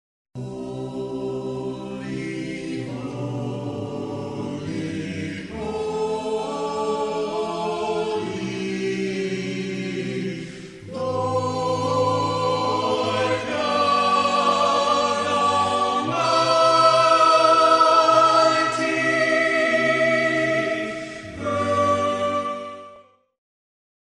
praise